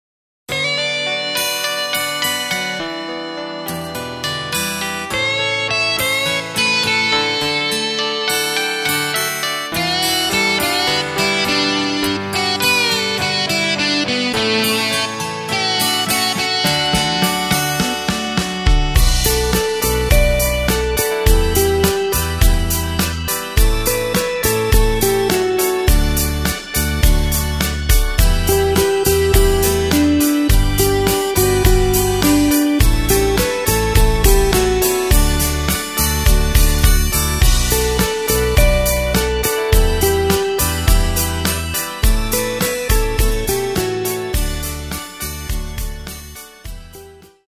Takt:          4/4
Tempo:         104.00
Tonart:            D
Austropop aus dem Jahr 1978!